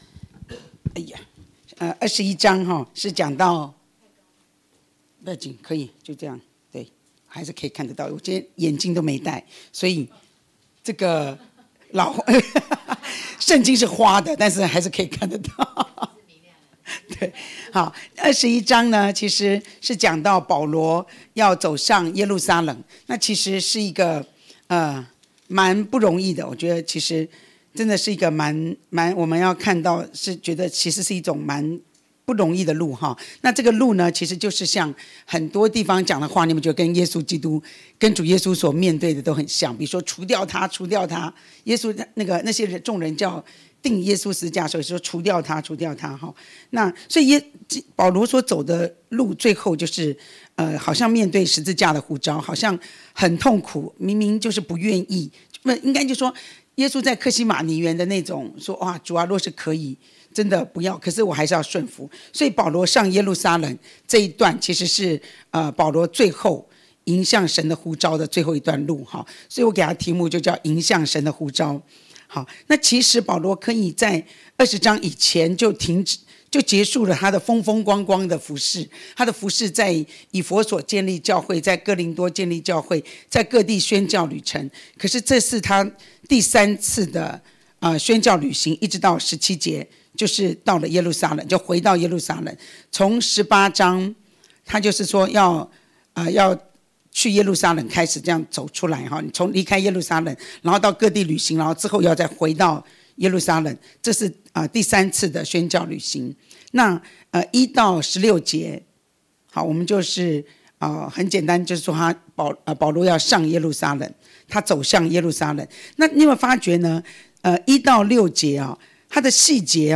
晨禱 使徒行傳21～22章 – 神住611靈糧堂